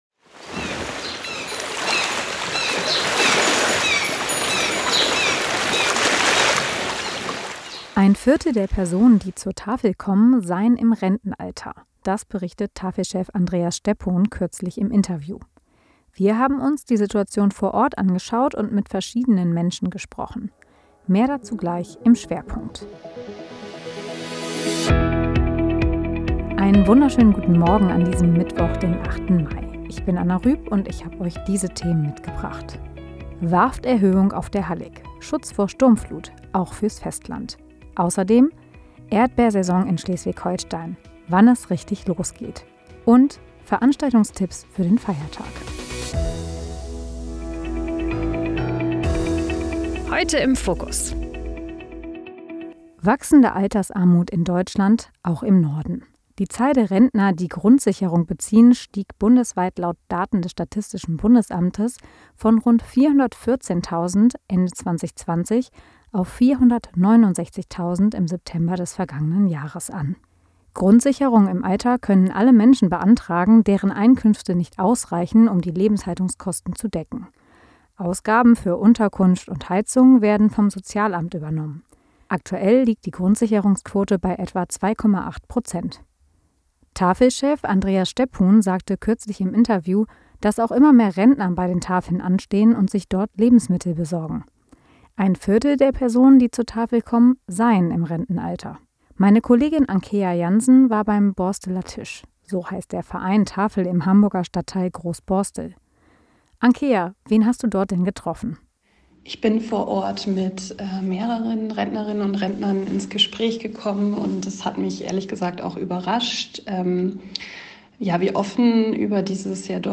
Wir haben uns die Situation vor Ort angeschaut und mit verschiedenen Menschen gesprochen.